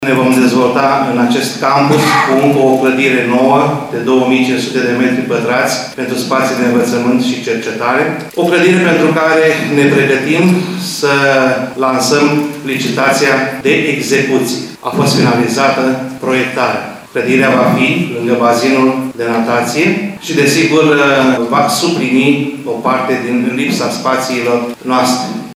El a declarat, la festivitatea de la debutul anului universitar, că noile dotări vor suplini o parte din spațiile care acum lipsesc.